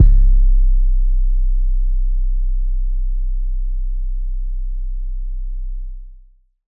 Metro 808 1.wav